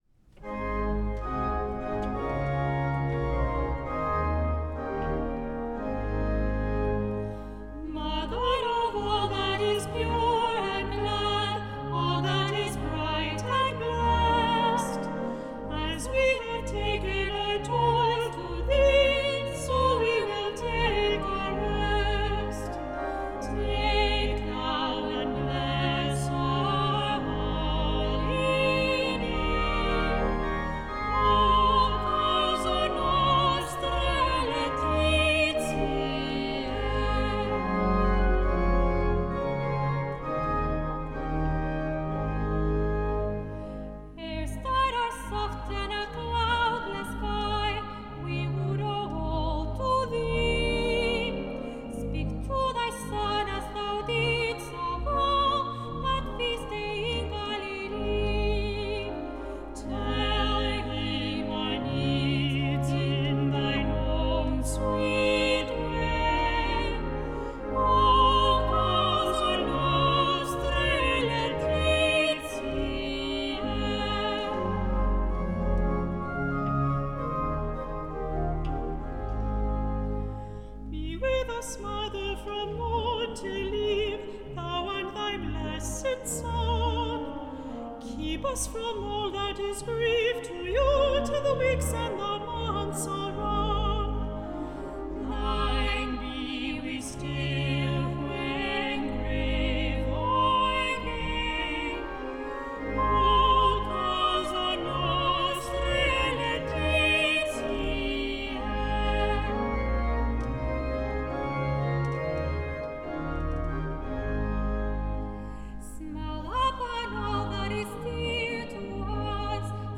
Recorded at St. Paul’s R.C. Church, Cambridge, MA, August 2014